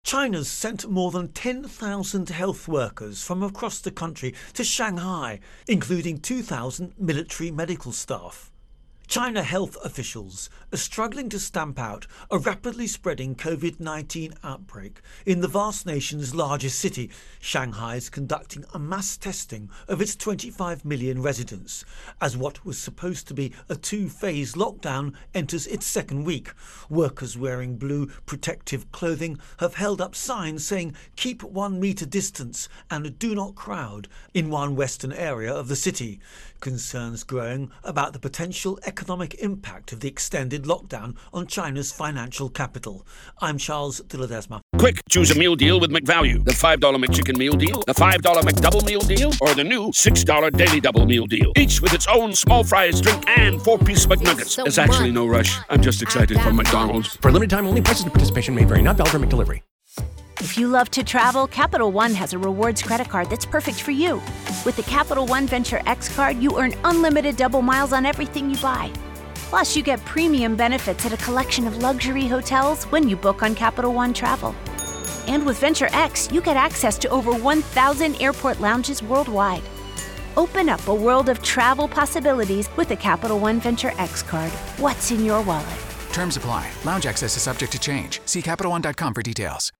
Virus Outbreak-China Intro and Voicer